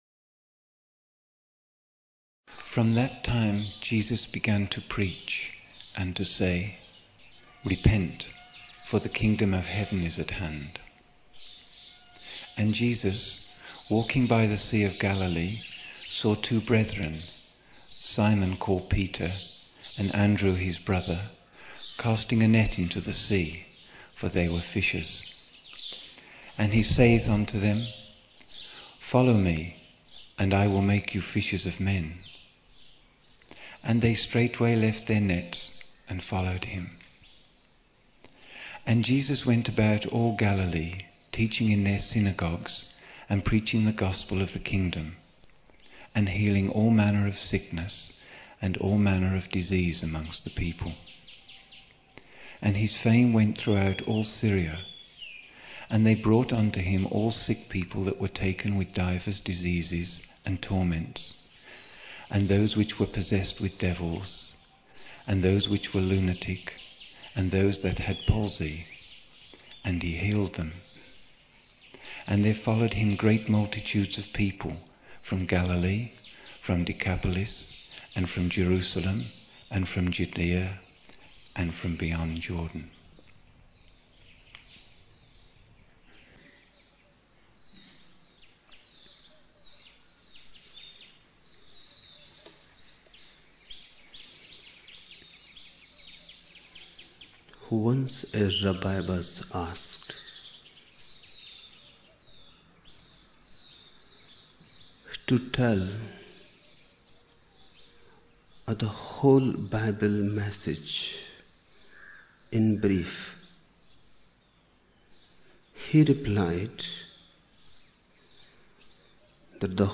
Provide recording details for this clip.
25 October 1975 morning in Buddha Hall, Poona, India